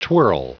Prononciation du mot twirl en anglais (fichier audio)
Prononciation du mot : twirl